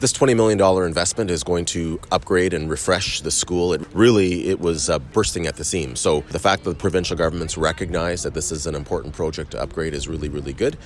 Belleville Mayor Mitch Panciuk says this is great news for the city.